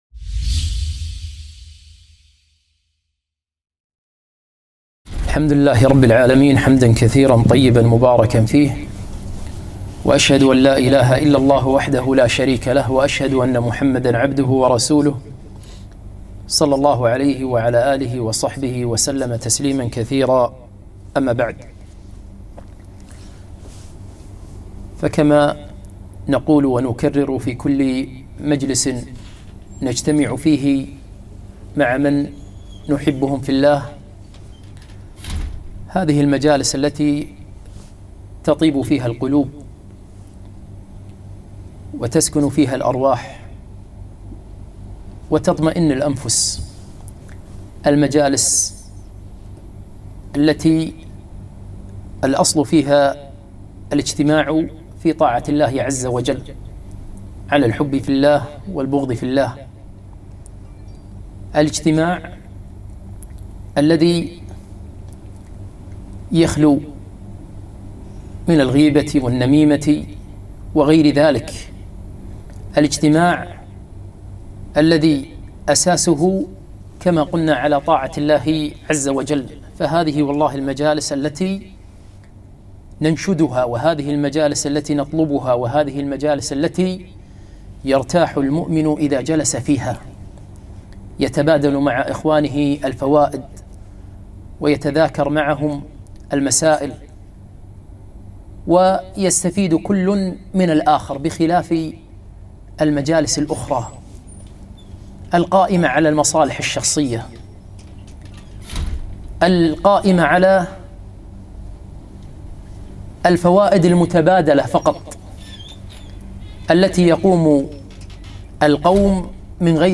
محاضرة - الحرص على الجاه والمناصب 8 صفر 1442هـ